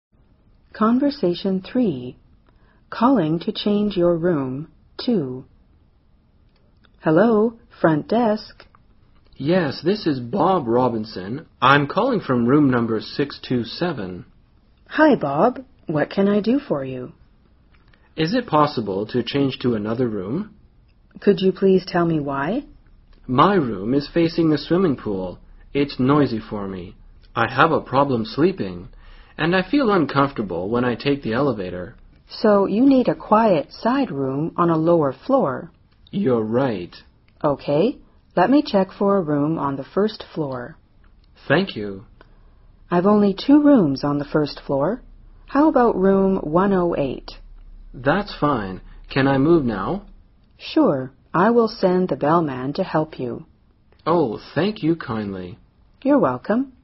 【对话3：打电话要求换房间（2）】